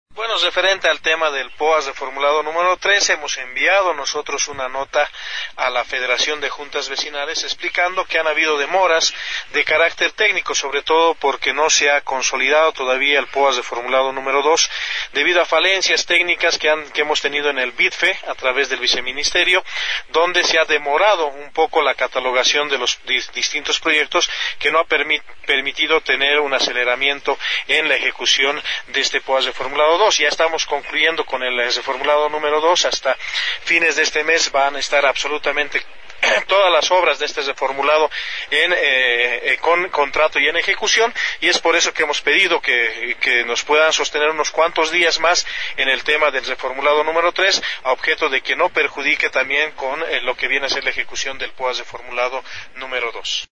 Henry Contreras, Secretario Municipal de Gobernabilidad informó a este medio de comunicación que se envió una nota informativa a la Fejuve informando la falta de ejecución de las obras del POA II debido a las observaciones técnicas del Viceministerio de Inversión Pública y Financiamiento Externo (VIPFE).